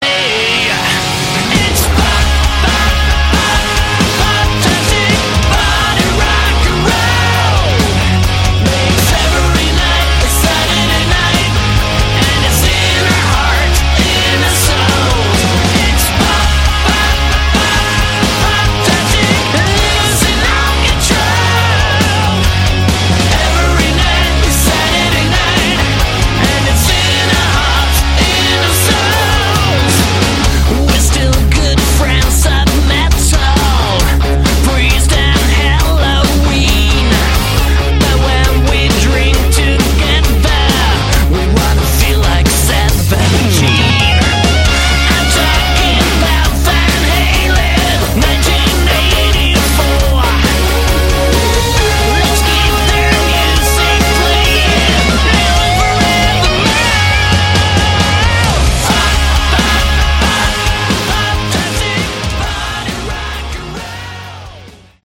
Category: Hard Rock
lead vocals, guitar
guitar, vocals
bass, vocals
drums, vocals